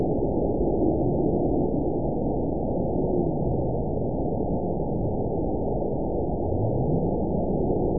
event 921099 date 04/28/24 time 19:08:15 GMT (1 year ago) score 9.07 location TSS-AB06 detected by nrw target species NRW annotations +NRW Spectrogram: Frequency (kHz) vs. Time (s) audio not available .wav